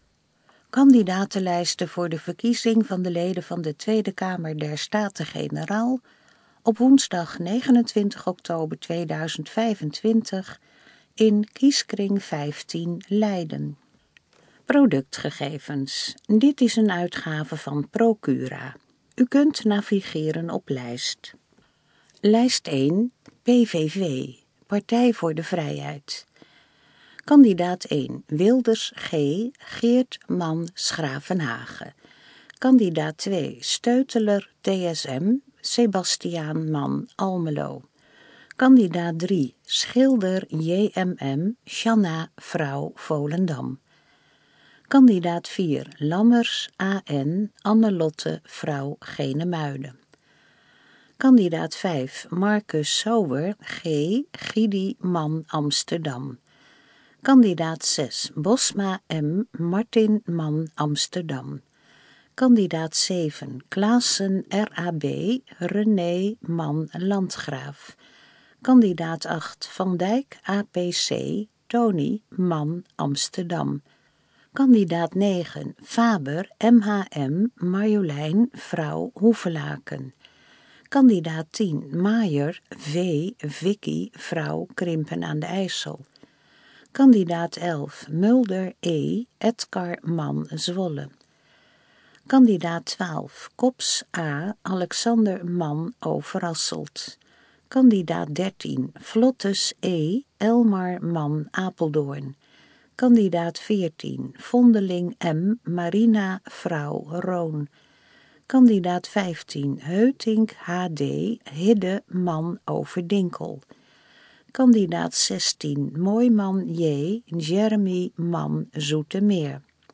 Kandidatenlijst in gesprokken vorm (mp3 bestand)